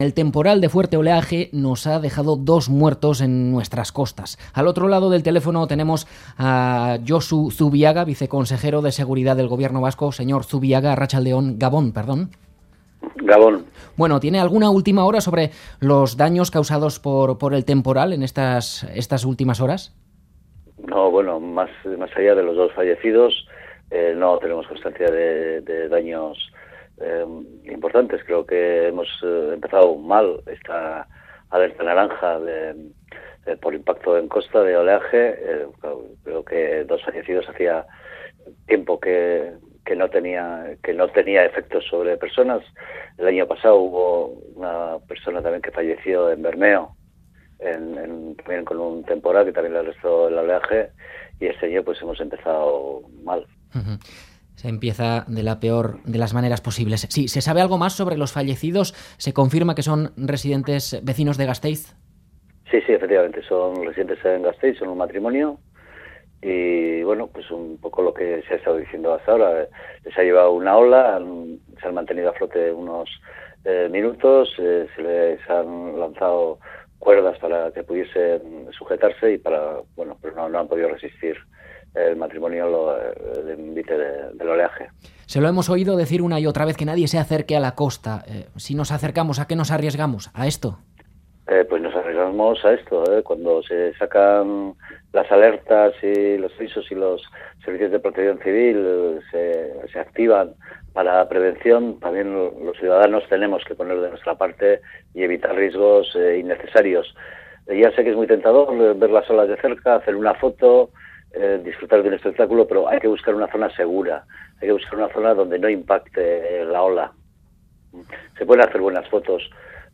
Radio Euskadi GANBARA 'Es tentador ver las olas de cerca, pero hay que buscar una zona segura' Última actualización: 03/01/2018 22:47 (UTC+1) Entrevista en Ganbara a Josu Zubiaga, Viceconsejero de Seguridad del Gobierno Vasco.